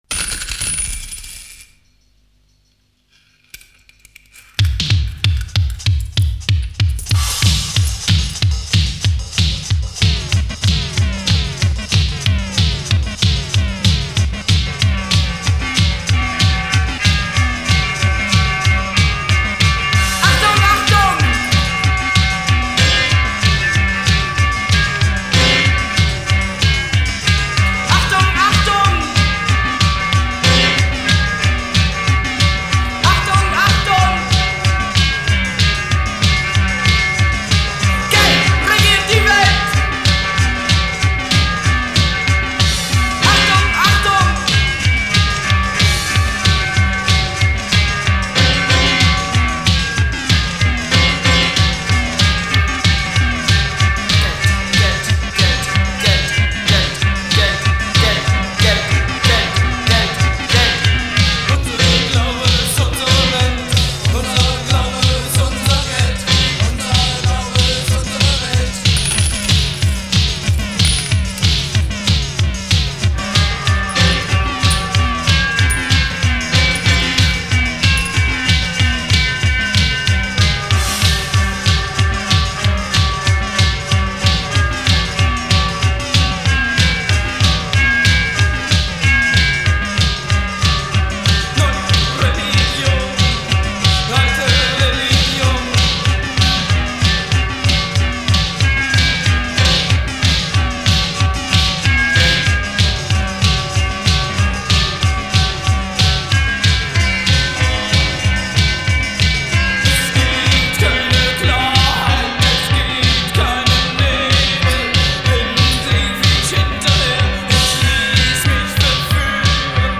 Género: Pop.